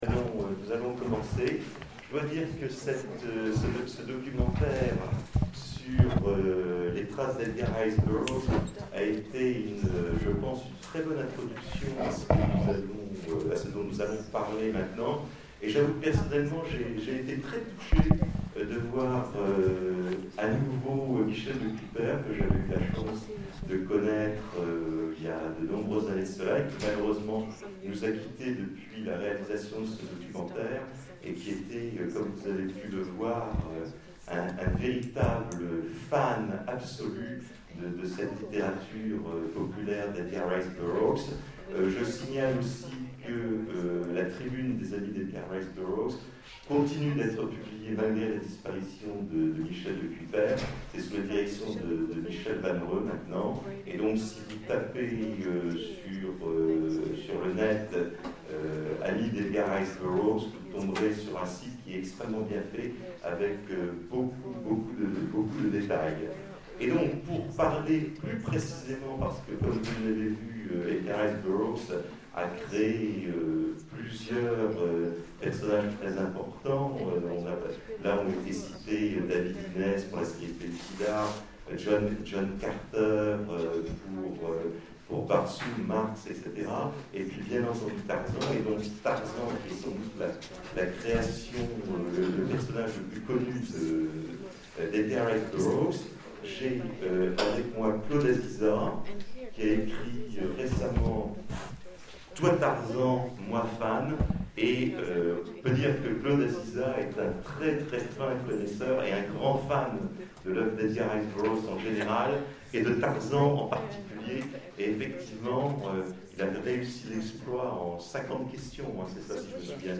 Etonnants Voyageurs 2013 : Conférence Voyageurs intrépides du réel et de l'imaginaire